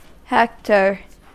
Ääntäminen
IPA : /hɛk.tɚ/